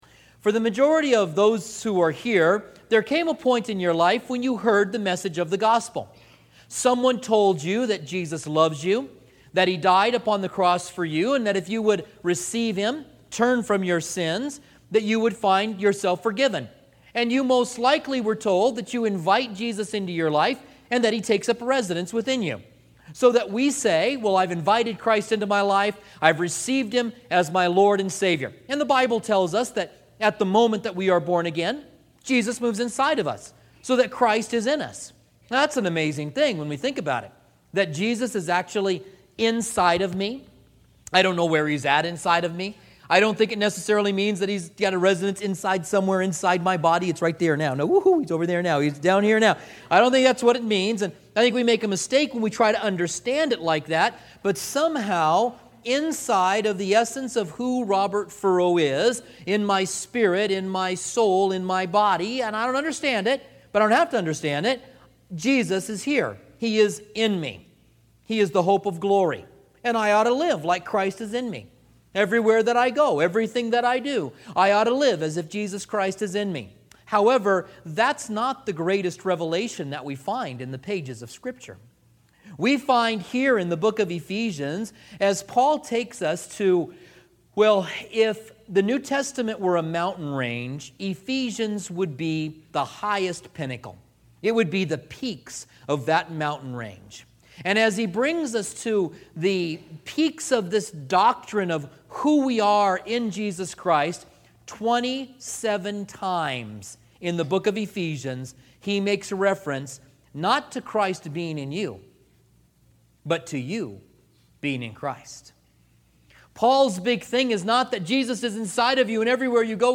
Commentary on Ephesians